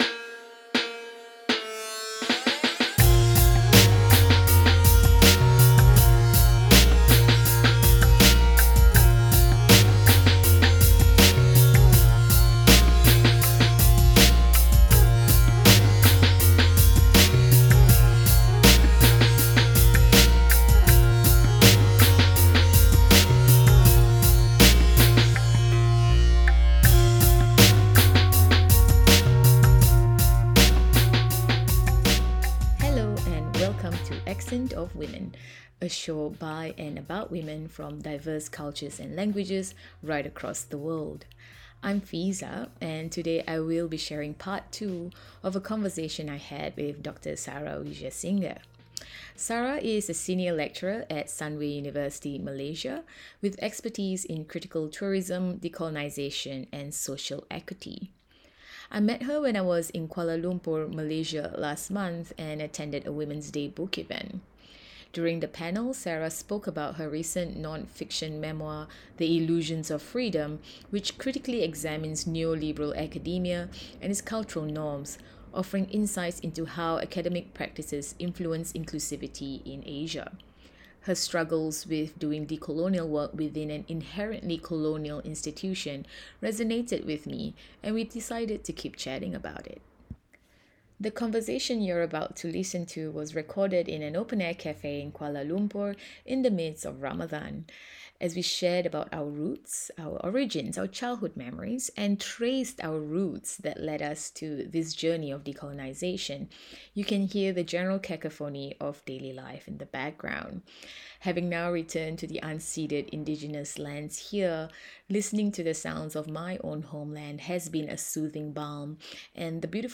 At the Socialism 2022 conference, held in September 2022, in Chicago